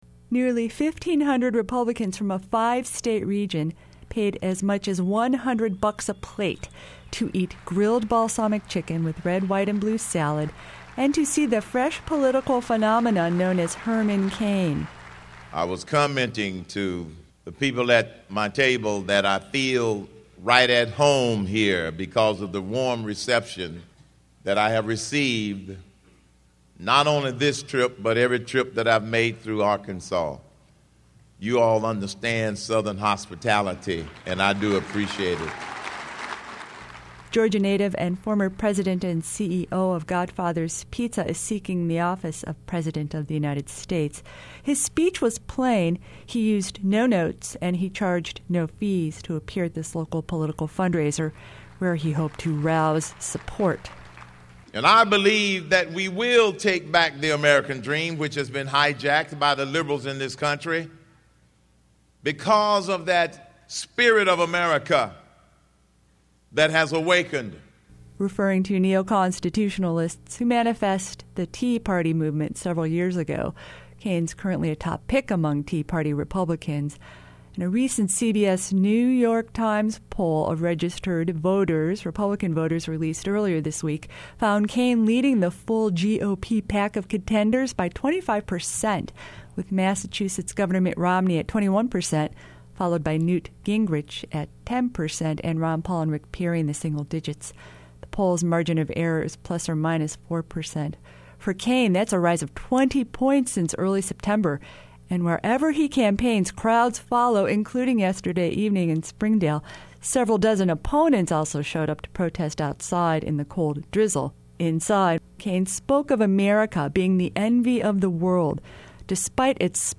Businessman, radio personality and author Herman Cain delivered a keynote address to the Washington County Republican Party Lincoln Day Dinner in Springdale last night.